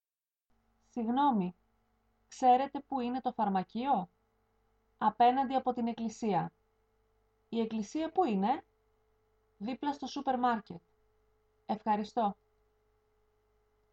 Dialog C: